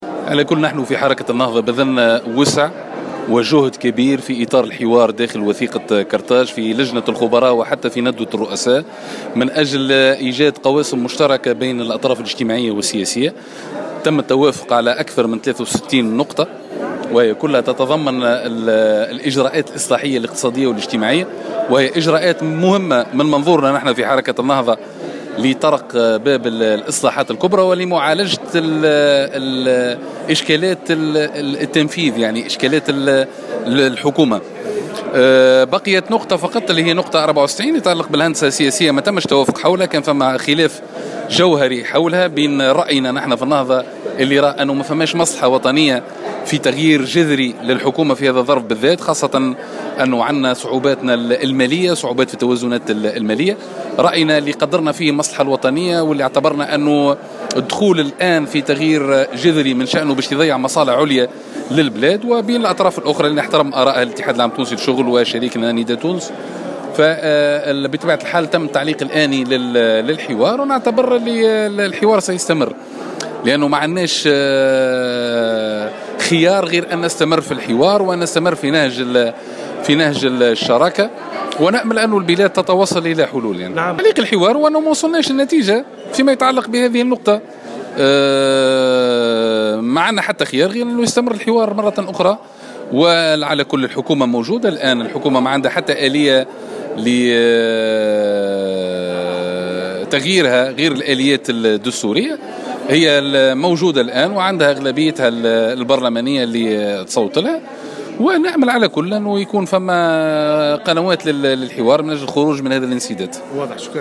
قال الناطق الرسمي باسم حركة النهضة عماد الخميري في تصريح لمراسل الجوهرة "اف ام" أن بذلت جهدا في اطار الحوار داخل وثيقة قرطاج سواء على مستوى لجنة الخبراء أو ندوة الرؤساء من أجل ايجاد قواسم مشتركة بين الأطراف السياسية المتحاورة.